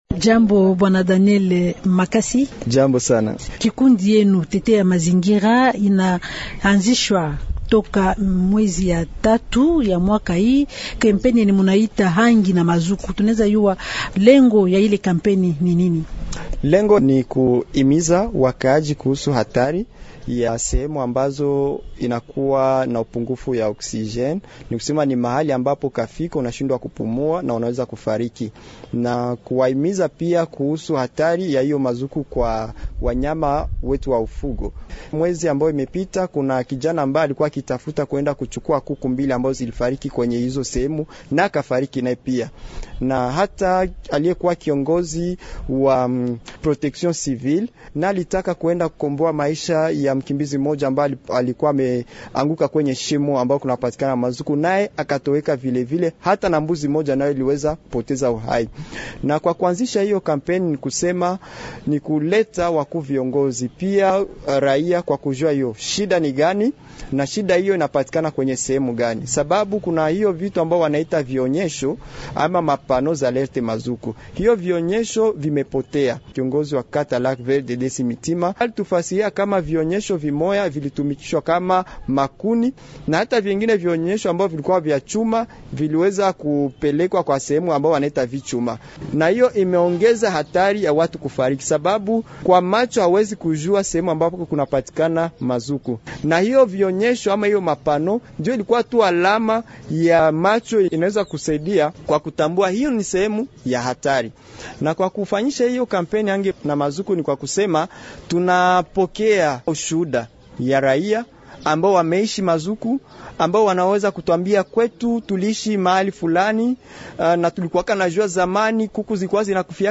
invite_swahili_de_ce_jeudi_matin_.mp3